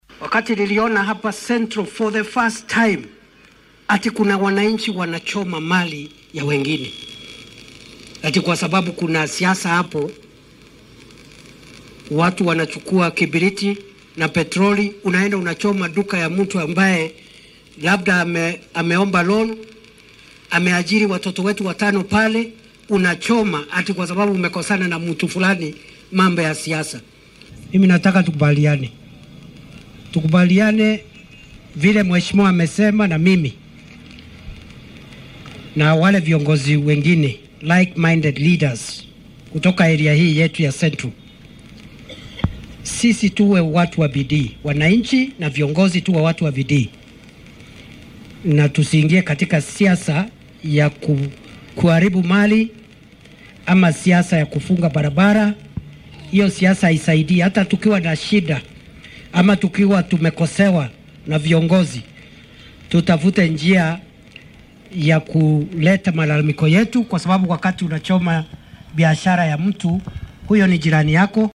Madaxweyne ku xigeenka dalka Prof. Kithure Kindiki ayaa ku booriyay dadka ka soo jeeda gobolka bartamaha dalka in ay joojiyaan siyaasadaha isdiidan oo ay diirada saaraan horumarka. Isagoo ka hadlayay suuqa kibingoti ee deegaanka Ndia ee ismaamulka Kirinyaga oo uu ku wehliyo Xildhibaanka laga soo doortay Ndia ayuu madaxweyne ku xigeenka sheegay in uu markii ugu horeysay hadal ka quustay in uu arko dadka gobolka Mt. Kenya oo qudhunaya hantida dadka kale sababo la xiriira siyaasad isdiidan.